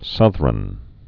(sŭthrən)